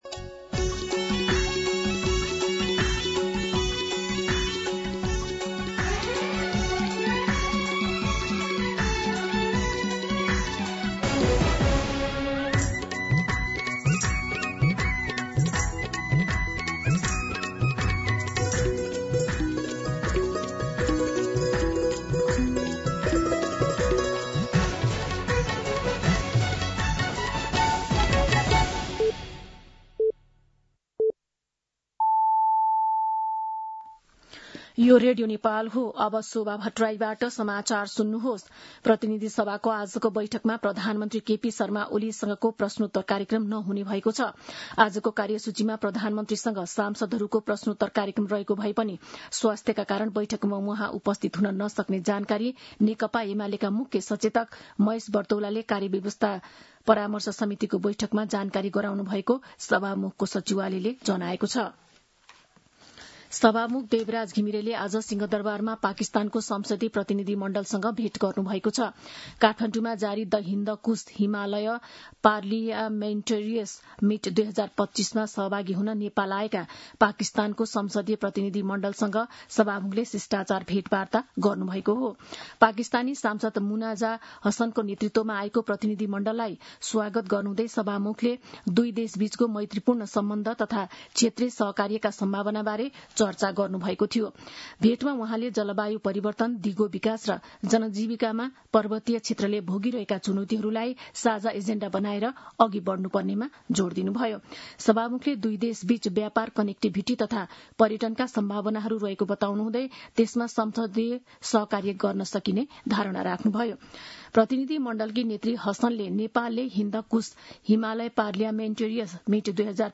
दिउँसो १ बजेको नेपाली समाचार : ४ भदौ , २०८२